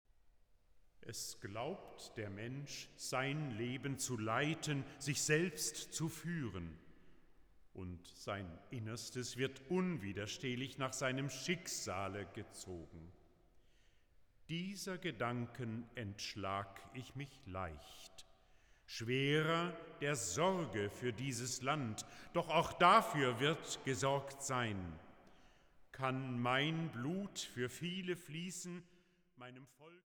Oboe
Trompete